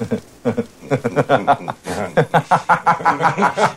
• FUNNY MULTIPLE LAUGH.wav
FUNNY_MULTIPLE_LAUGH_QD3.wav